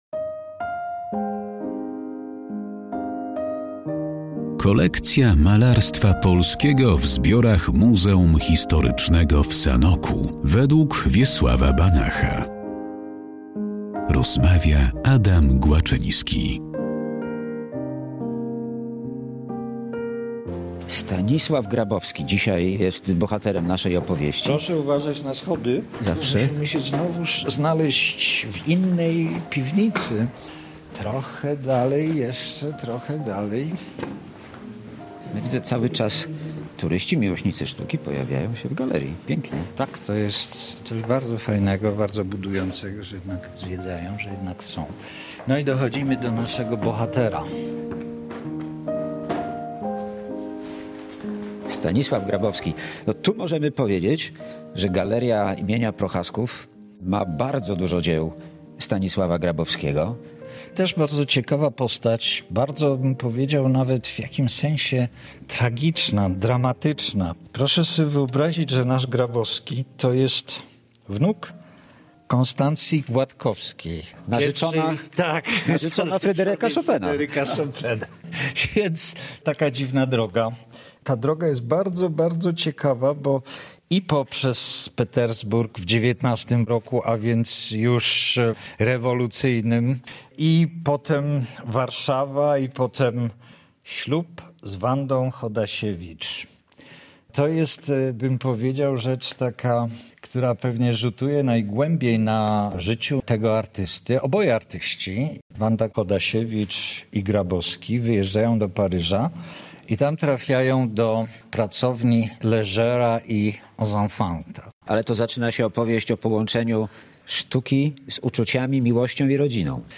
O twórczości Stanisława Grabowskiego i jego pracach znajdujących się w Muzeum Historycznym w Sanoku rozmawiają: